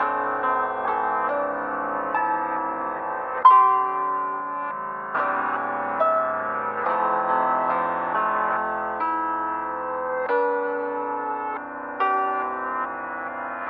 悲伤的钥匙，有反向的钥匙
描述：钢琴与反向钢琴背景
Tag: 140 bpm Hip Hop Loops Piano Loops 2.31 MB wav Key : Unknown